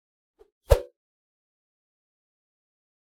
meleeattack-swoosh-light-group06-01.ogg